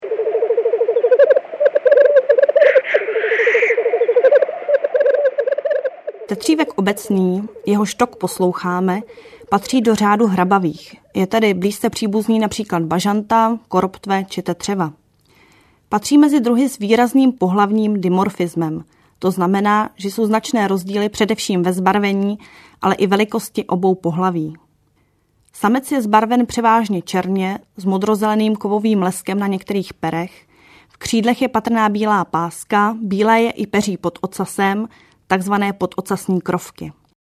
Komentované nahrávky 20 druhů ptáků